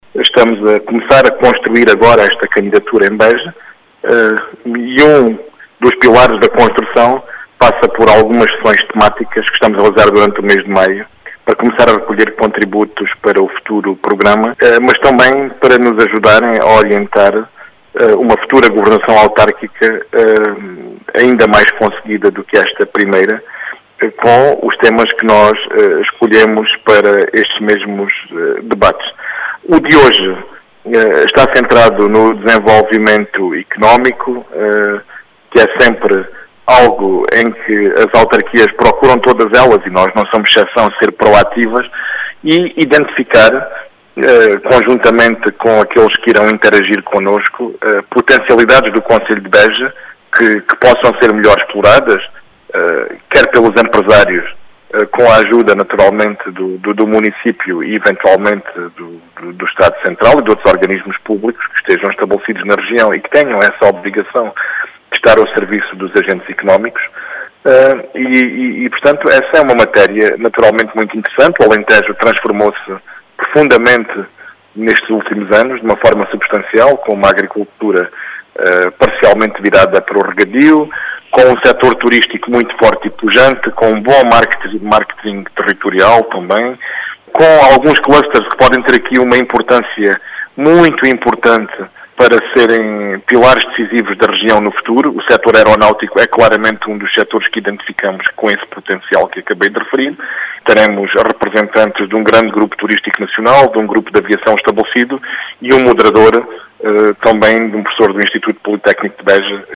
As explicações são de Paulo Arsénio, candidato do PS à Câmara Municipal de Beja.